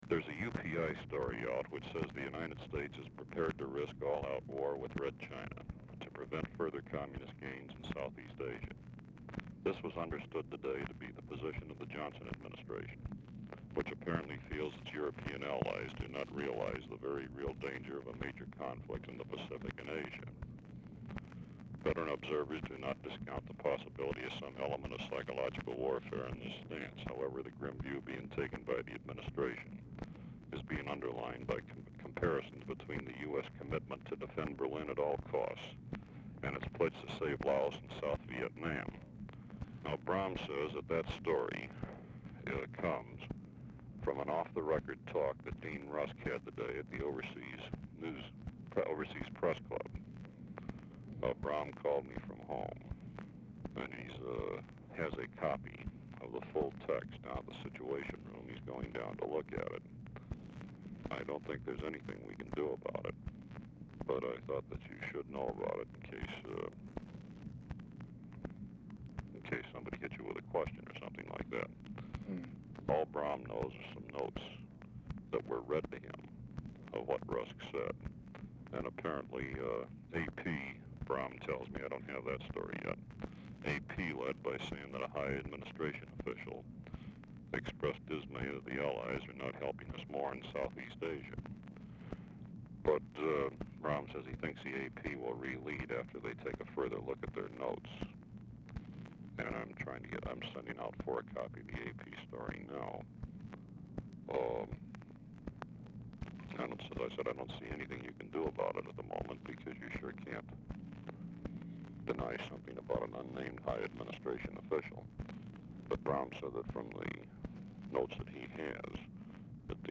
Telephone conversation # 3793, sound recording, LBJ and GEORGE REEDY, 6/19/1964, 7:03PM | Discover LBJ
Format Dictation belt
Specific Item Type Telephone conversation